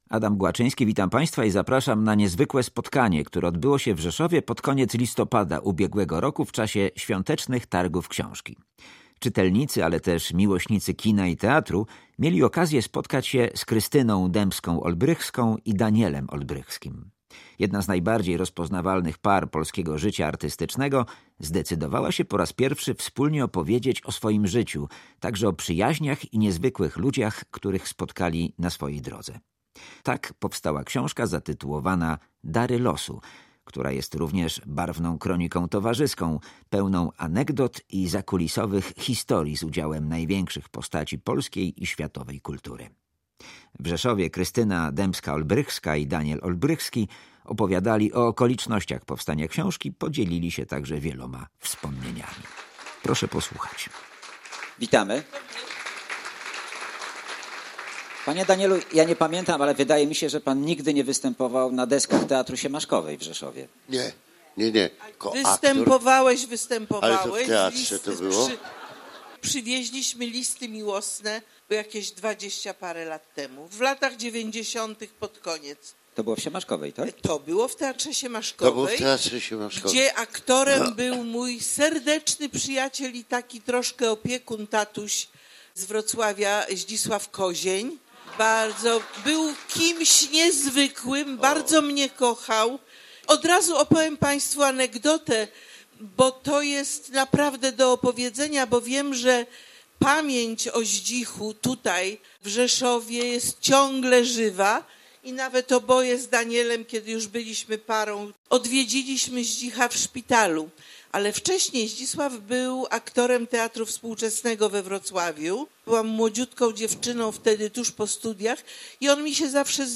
Okolice kultury • Pod koniec listopada, podczas Świątecznych Targów Książki w Rzeszowie, odbyło się spotkanie z Krystyną Demską-Olbrychską i Danielem Olbrychskim. Artyści opowiadali o książce „Dary losu” oraz o ludziach i wydarzeniach, które ukształtowały ich życie.
Spotkanie z czytelnikami podczas targów książki
Spotkanie cieszyło się dużym zainteresowaniem publiczności.